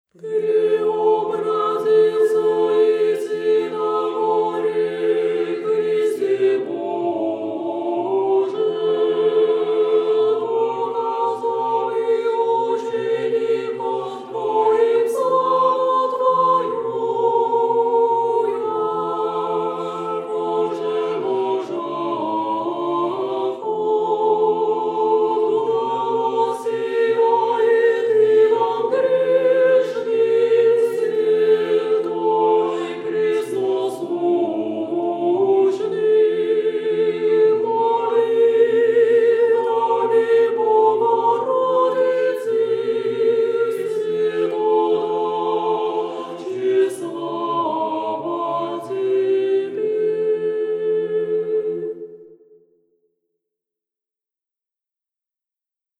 Тропарь-Преображение-Господне.mp3